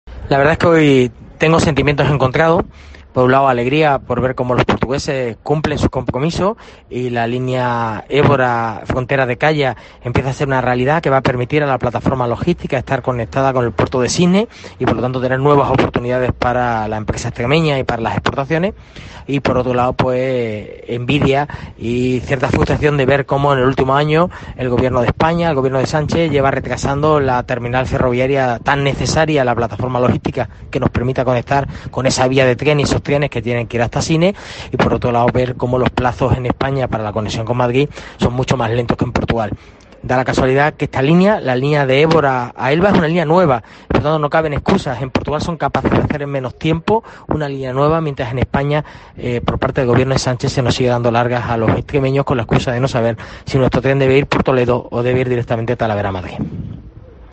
El Alcalde de Badajoz que ha estado en la firma del acta concesión del inicio de los trabajos del tramo ferroviario Alandroal-Elvas, hablaba para COPE